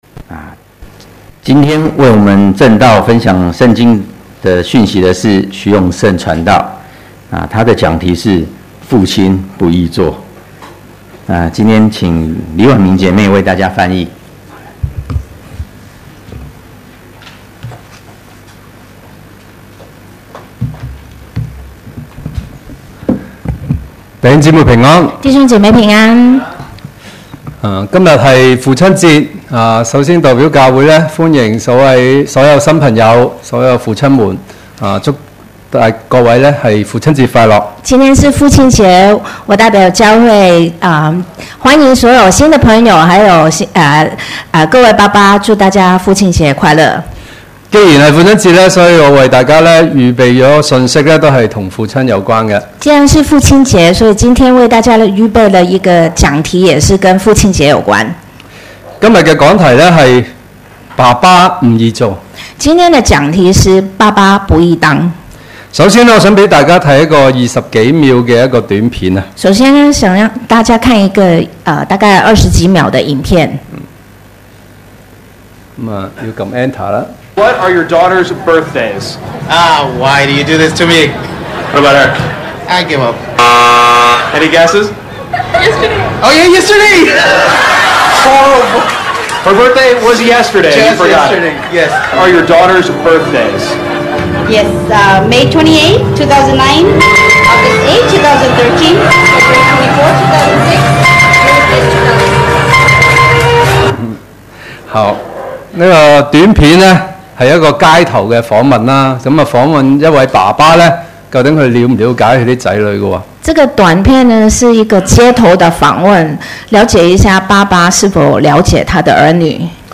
Posted in 主日崇拜 ← Newer 講道 Older 講道 →